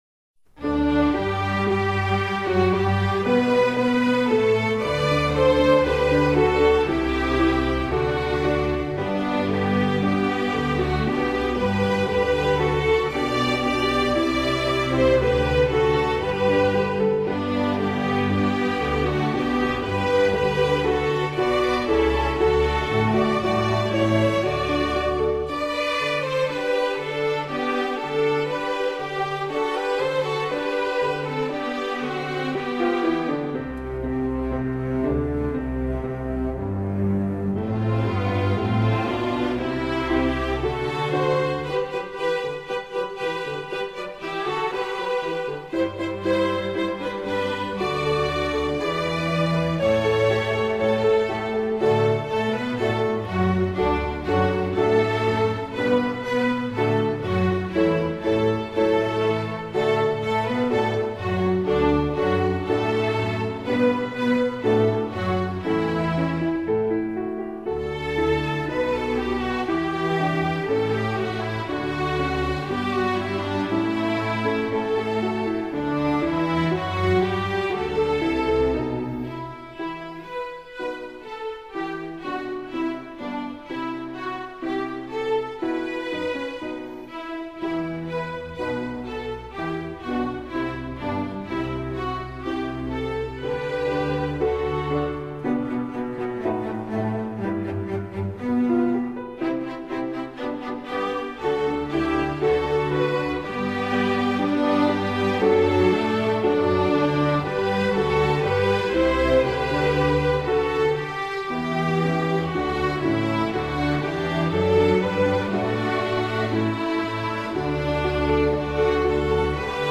Voicing: String Orchestra S